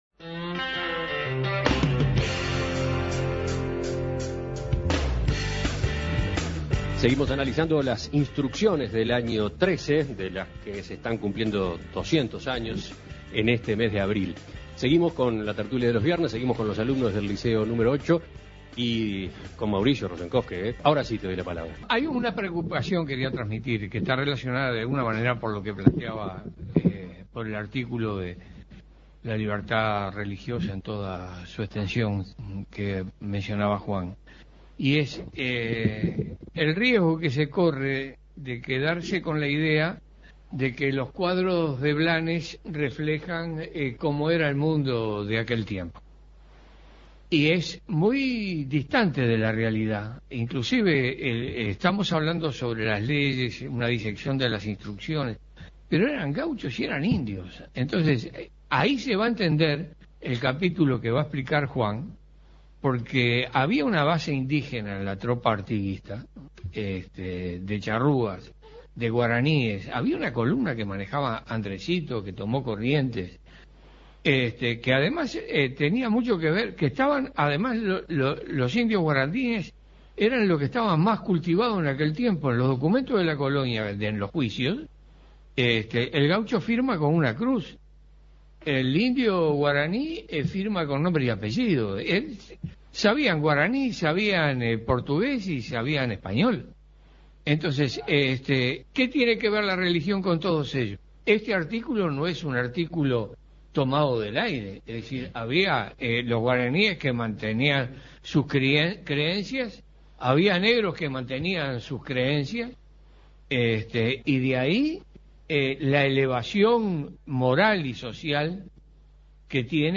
Tercera parte de La Tertulia especial desde el Liceo N° 8, conmemorando el bicentenario de las Instrucciones del año XIII